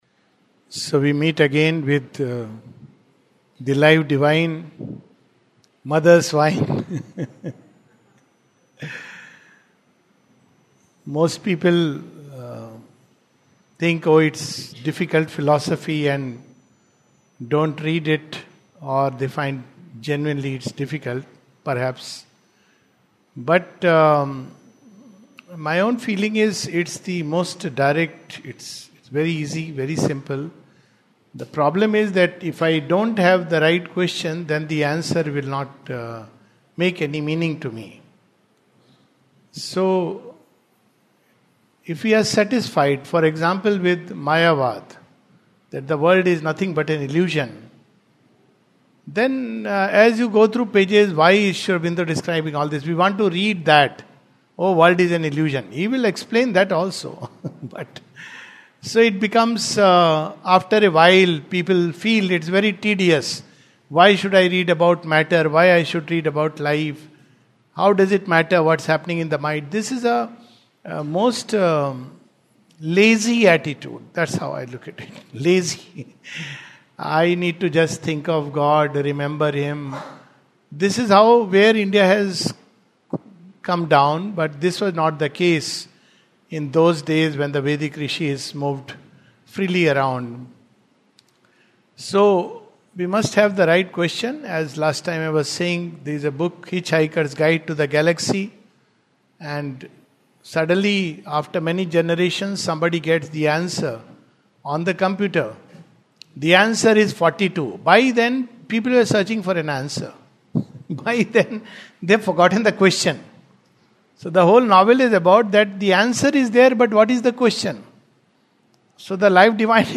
This is the talk covering portions of the third chapter of Book Two part One of the Life Divine.